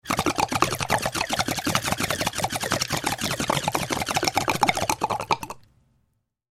Звуки бонга
Булькающий звук воды в бонге